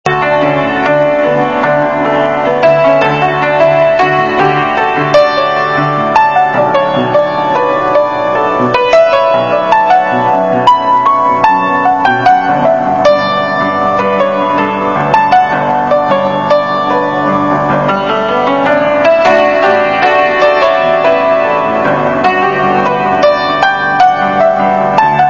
These are all what we call "rough cuts" in the music industry.
A Christmas song (music only)